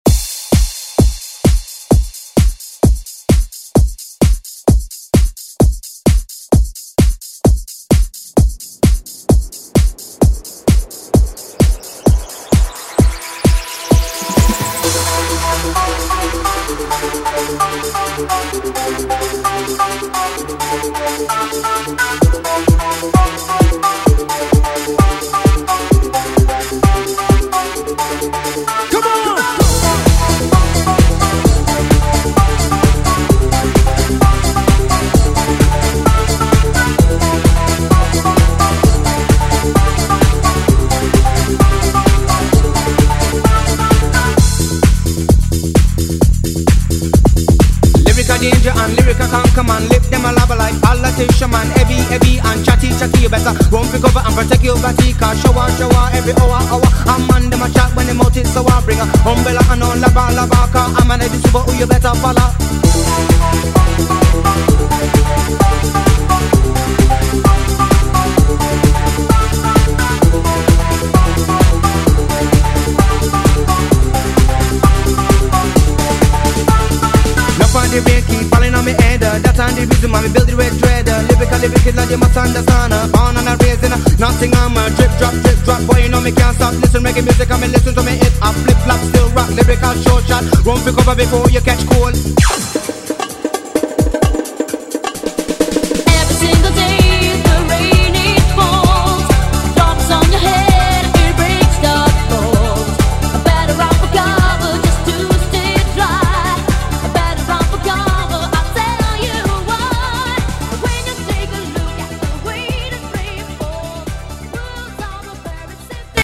Electronic Pop Rock
93 bpm
Genre: 90's